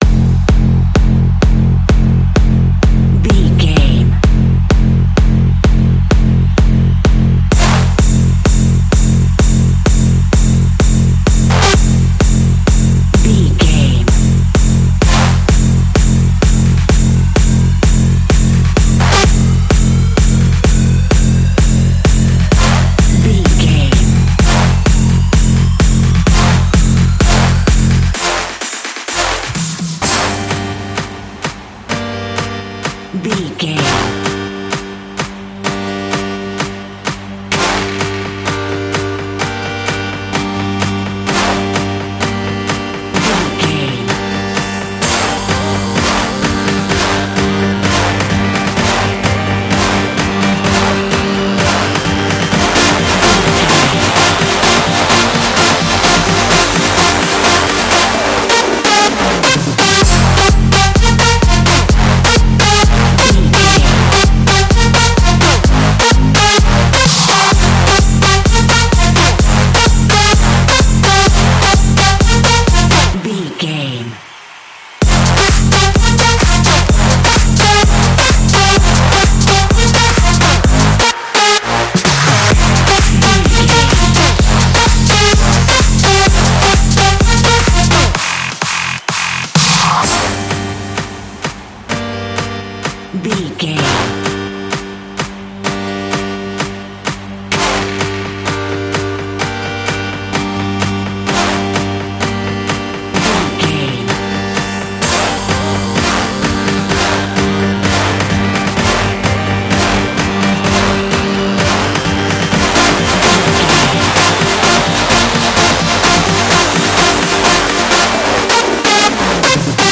Trance World Music.
In-crescendo
Aeolian/Minor
Fast
hypnotic
industrial
driving
energetic
frantic
dark
drum machine
synthesiser
acid house
uptempo
synth leads
synth bass